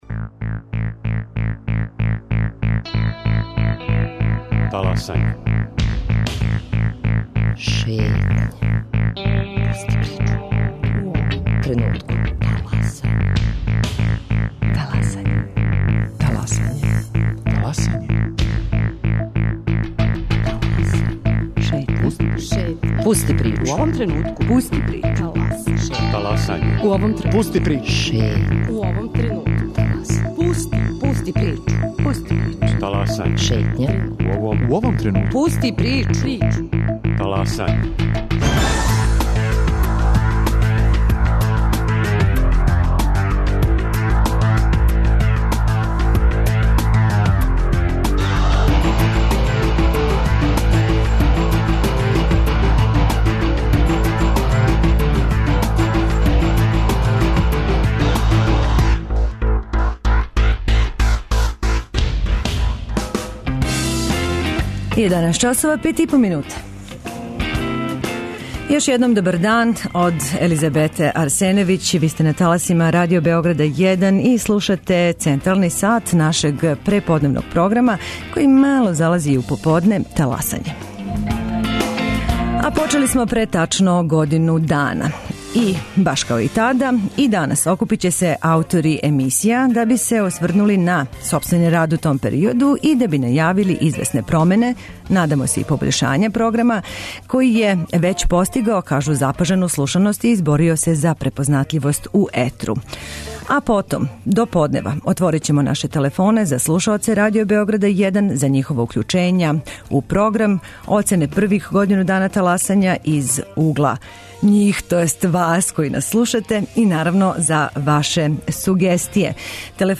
Потом, до поднева, отворићемо наше телефоне за слушаоце Радио Београда 1, за њихова укључења у програм, оцене првих годину дана Таласања и сугестије.